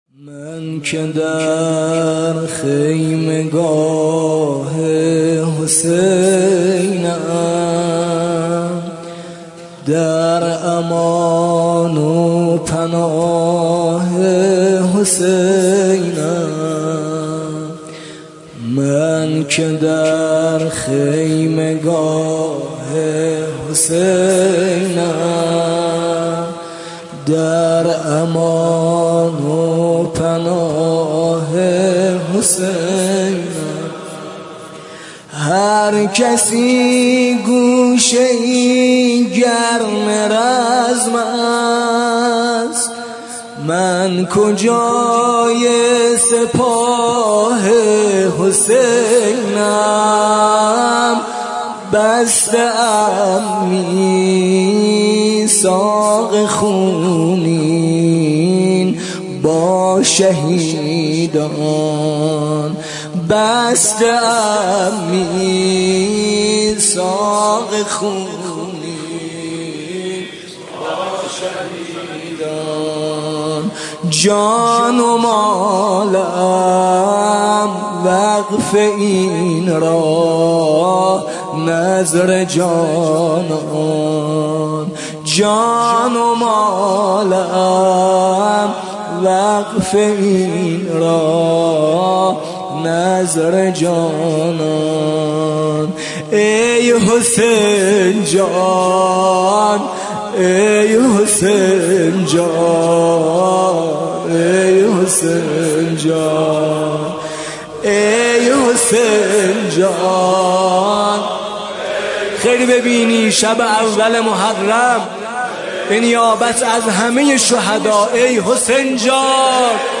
شب اول محرم97 هیات میثاق با شهدا، دانشگاه امام صادق (علیه‌السلام)